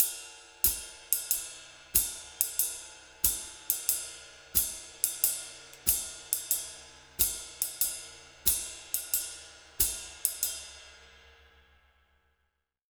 92SWING 01-L.wav